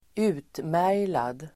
Ladda ner uttalet
Uttal: [²'u:tmär:jlad]